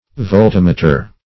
Voltammeter \Volt*am"me`ter\, n.